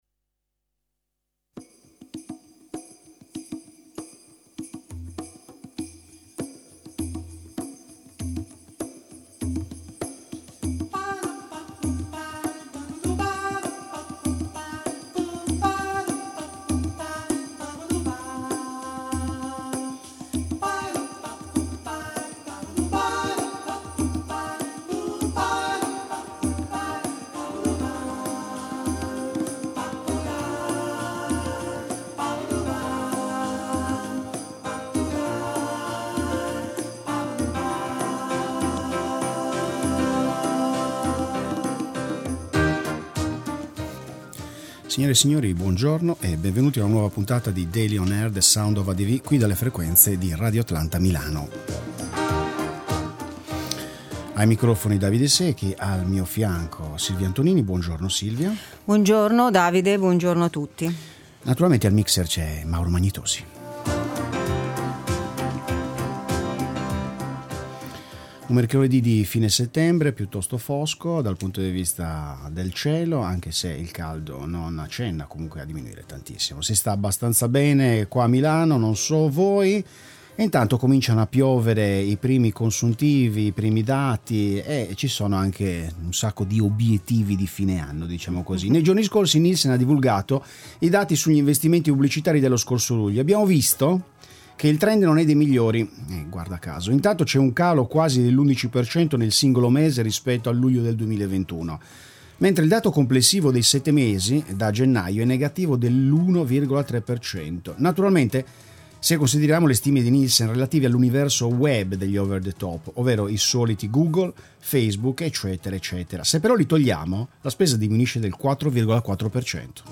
Influencer marketing, intervista